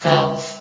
sound / vox_fem / gulf.ogg
CitadelStationBot df15bbe0f0 [MIRROR] New & Fixed AI VOX Sound Files ( #6003 ) ...